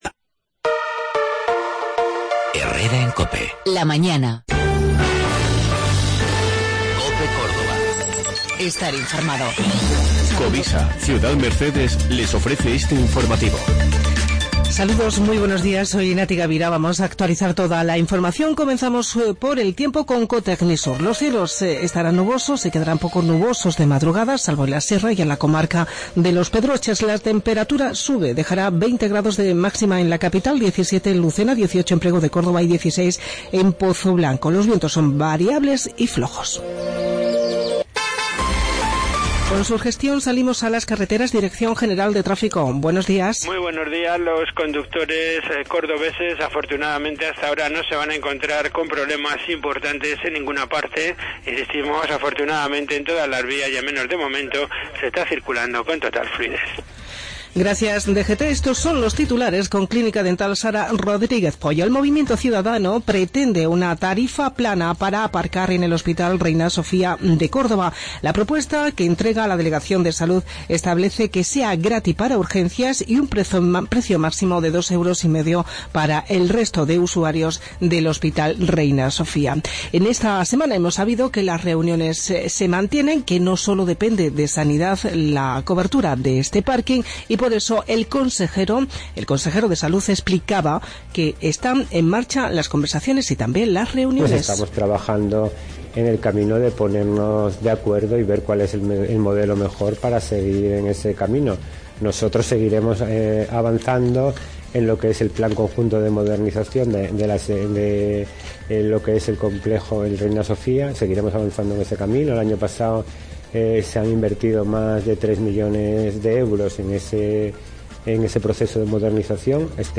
Informativo Herrera en Cope 8:20